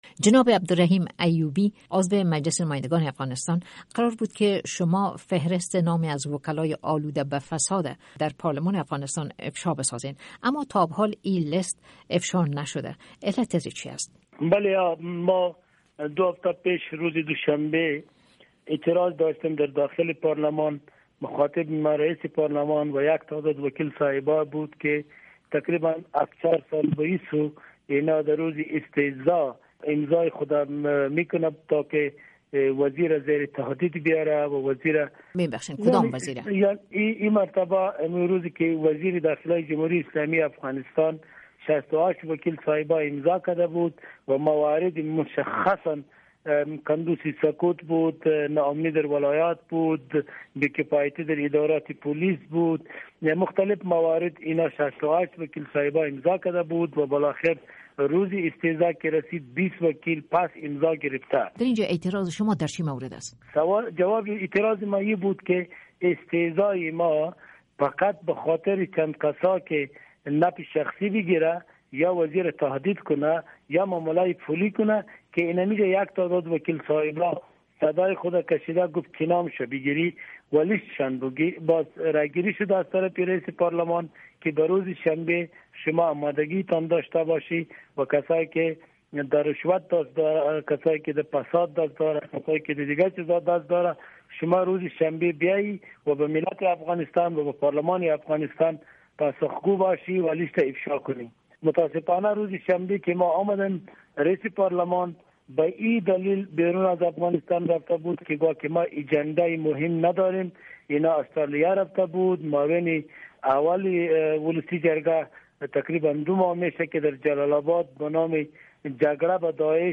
شرح بیشتر مصاحبه با عبدالرحیم ایوبی را از اینجا بشنوید: